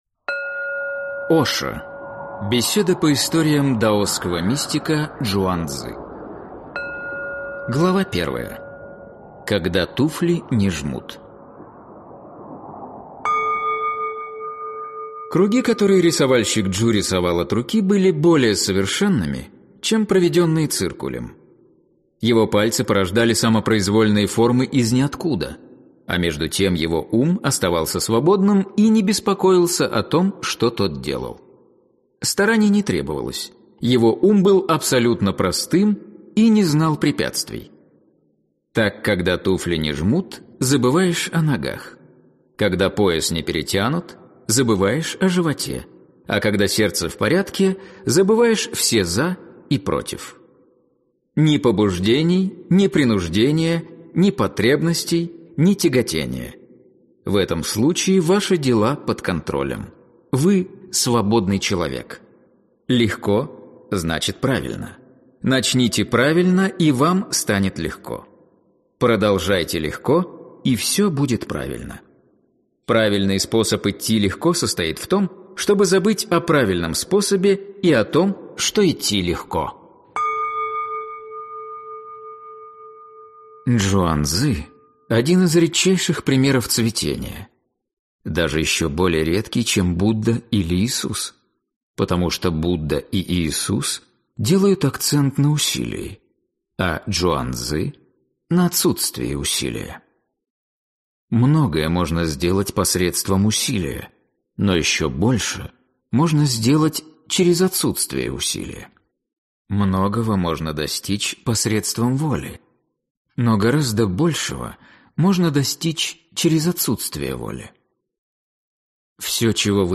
Аудиокнига Когда туфли не жмут. Беседы по историям даосского мистика Чжуан-цзы | Библиотека аудиокниг